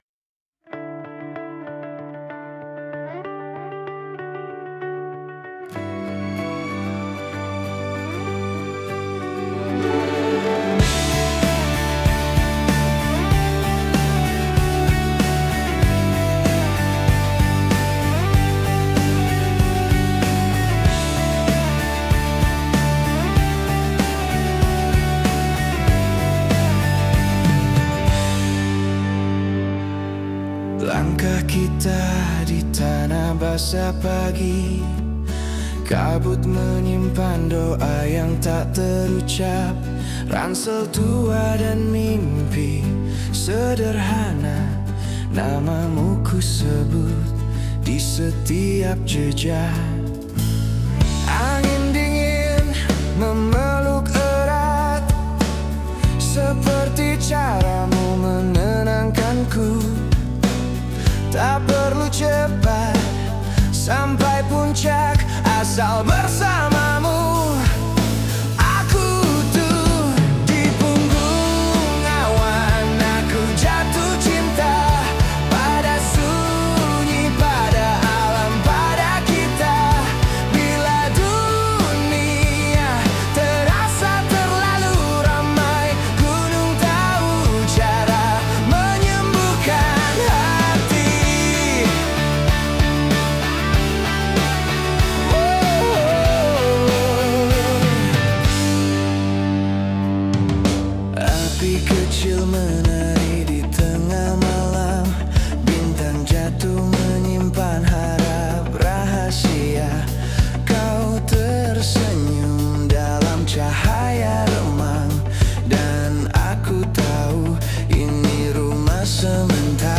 Lagu diciptakan dengan teknologi AI modern